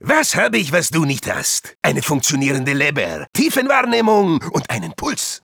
Voice file from Team Fortress 2 German version.
Spy_dominationdemoman07_de.wav